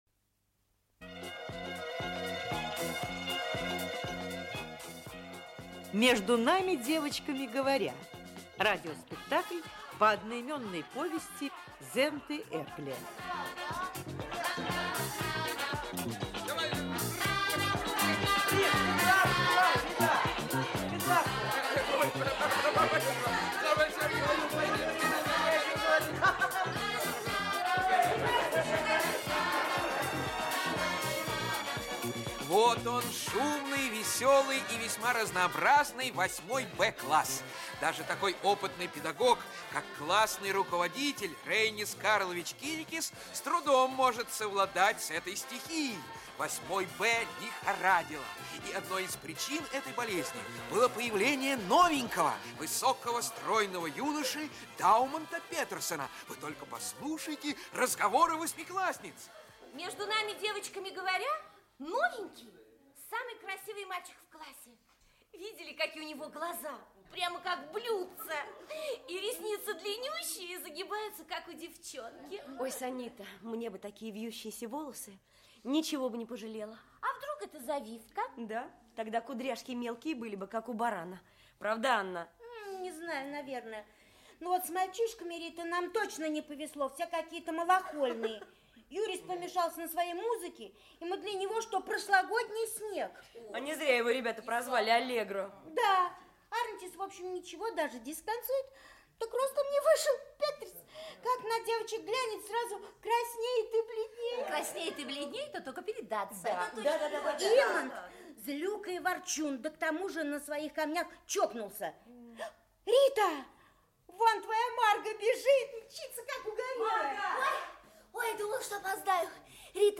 Aудиокнига Между нами, девочками, говоря… Автор Зента Эрнестовна Эргле Читает аудиокнигу Актерский коллектив.